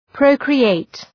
Προφορά
{‘prəʋkrı,eıt}